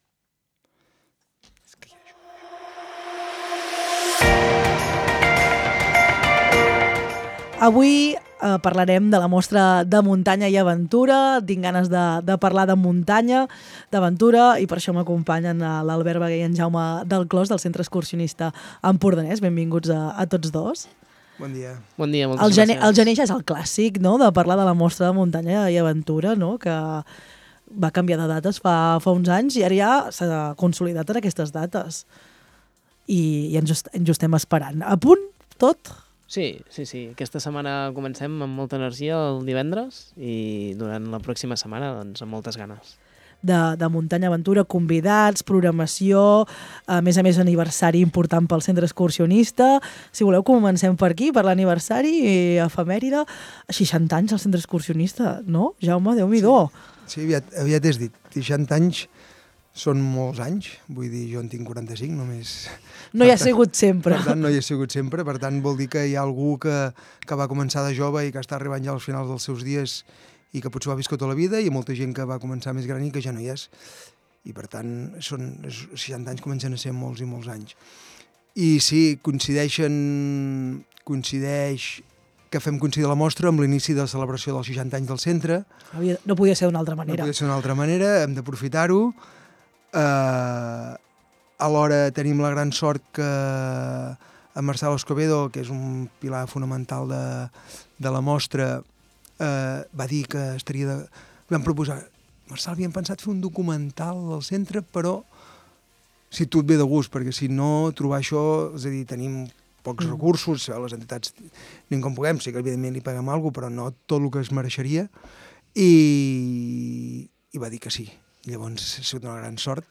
LVDM - ENTREVISTA - MOSTRA DE MUNTANYA 15 GENER 25~0.mp3